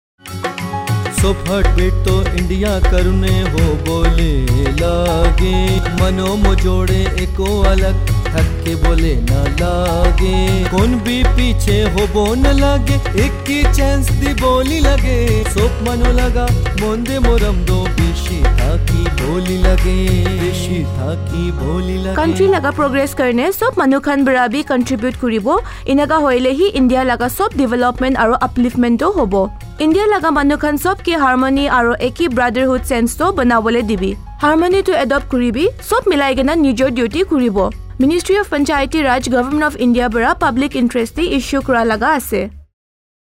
172 Fundamental Duty 5th Fundamental Duty Sprit of common brotherhood Radio Jingle Nagamese